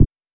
REDD PERC (36).wav